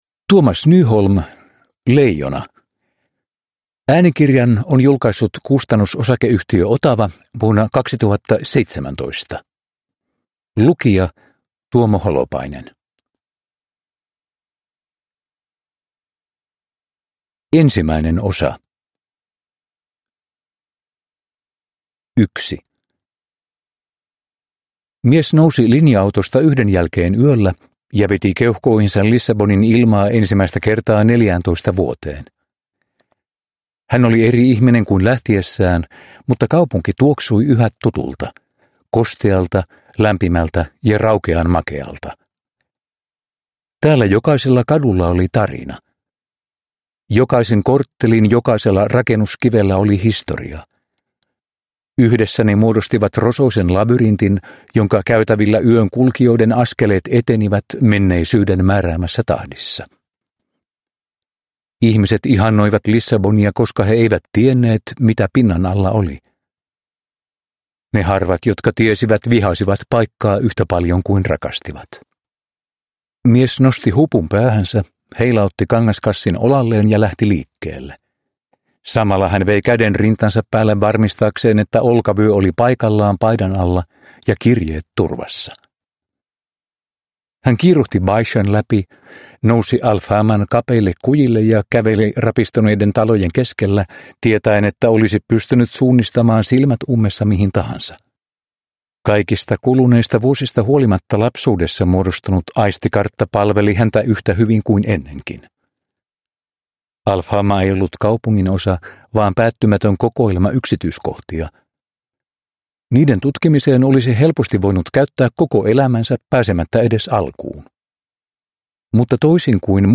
Leijona – Ljudbok – Laddas ner